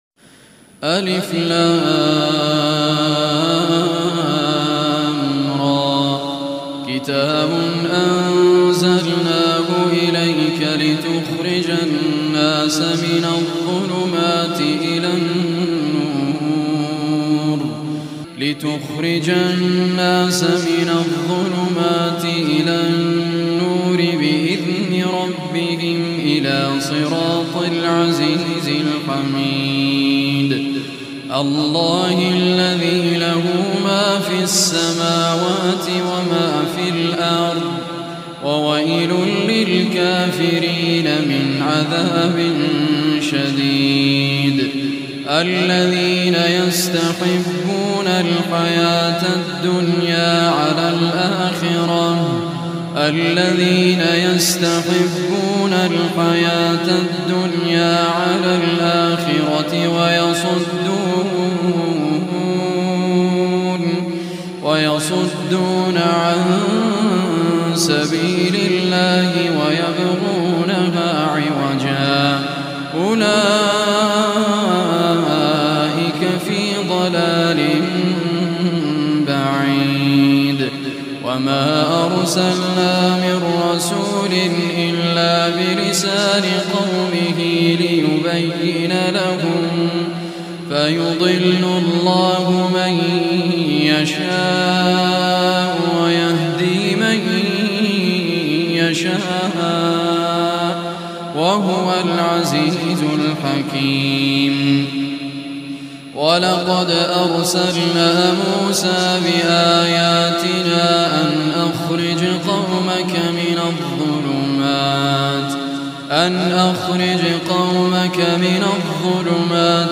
Surah Ibrahim Recitation by Raad al Kurdi
Surah Ibrahim, listen or play online mp3 tilawat recited by Raad Muhammad AL Kurdi.